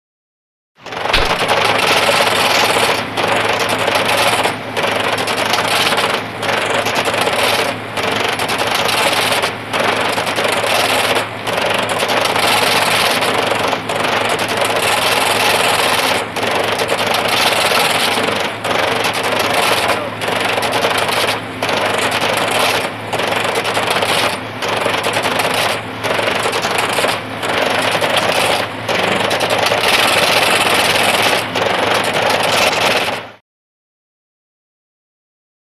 Sorting Machines
Card Sorting Machine; Card Sorting Machine.